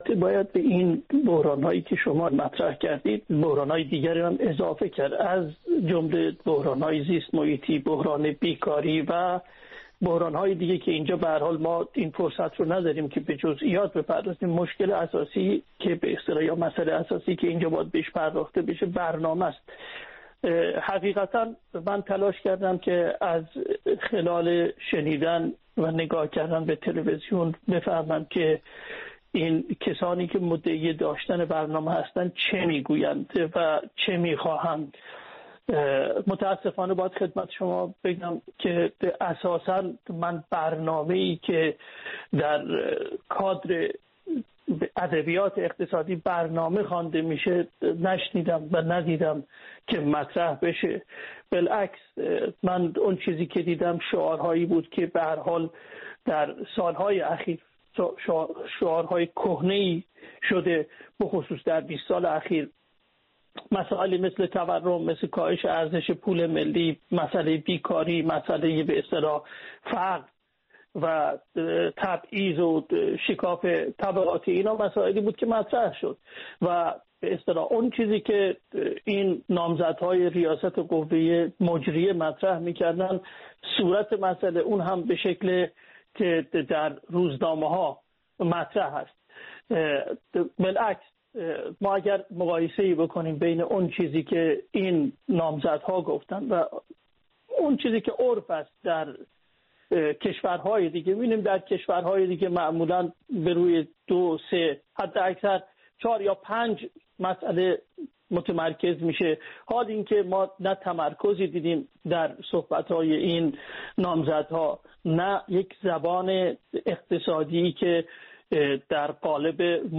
میزگردی
با حضور سه اقتصاددان